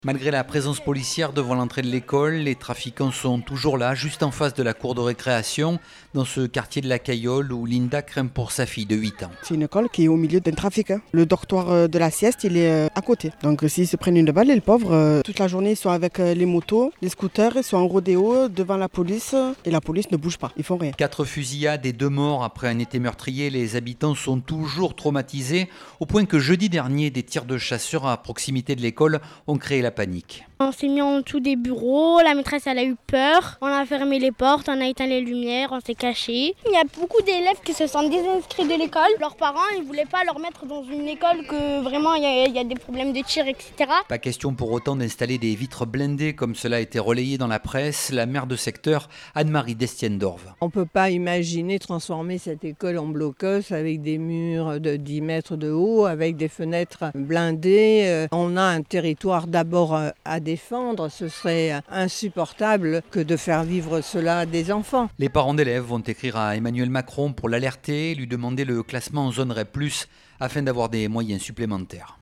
C'est ce qu'explique la maire de secteur, Anne Marie d’Estienne d’Orves.